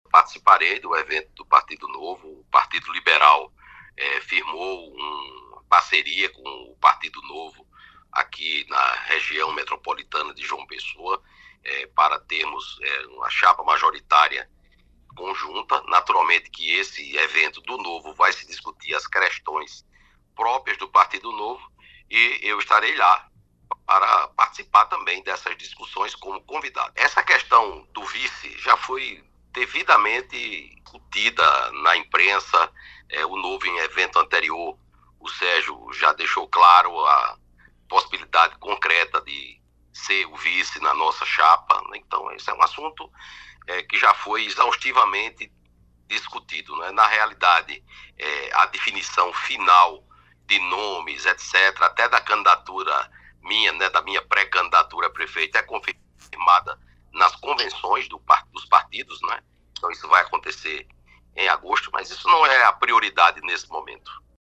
Os comentários do ex-ministro foram registrados pelo programa Correio Debate, da 98 FM, de João Pessoa, nesta quarta-feira (22/05).